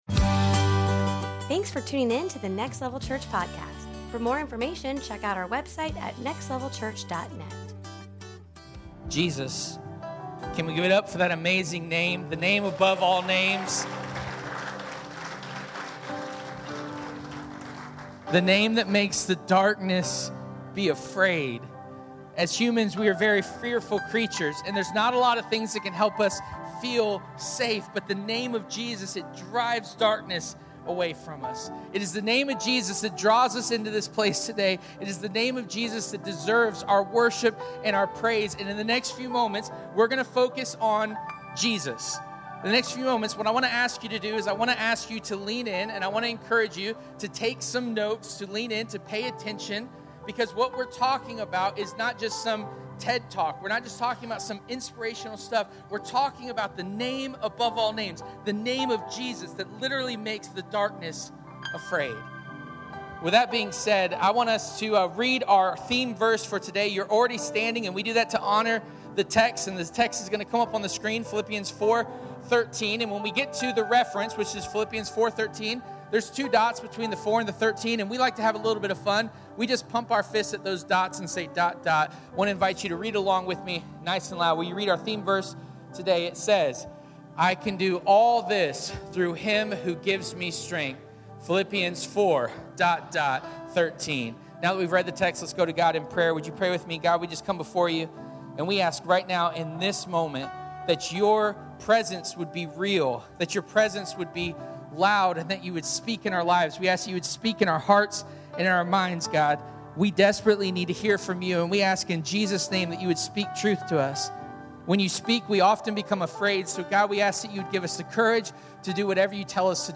Service Type: Sunday Morning “That’s not fair” is one of the earliest phrases we learn as humans.